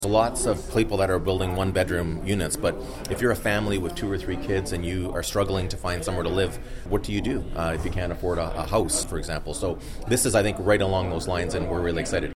Mayor Mitch Panciuk tells Quinte News it fits in with what the city needs.